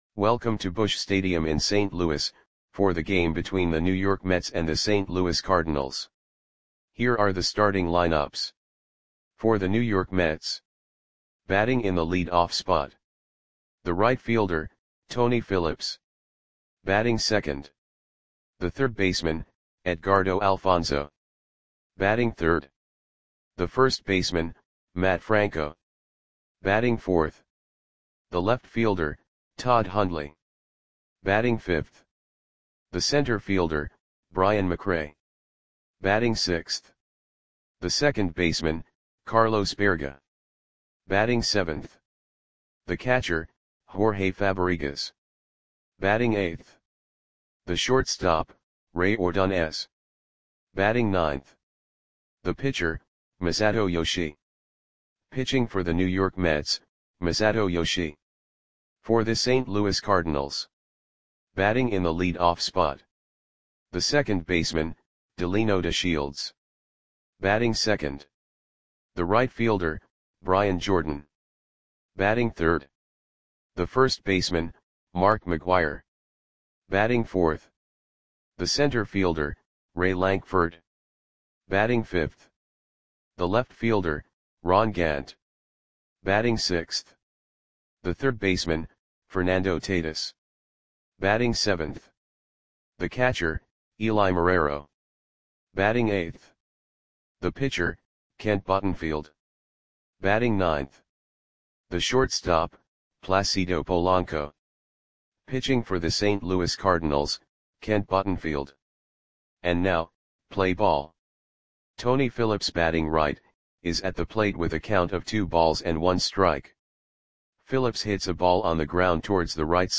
Audio Play-by-Play for St. Louis Cardinals on August 12, 1998
Click the button below to listen to the audio play-by-play.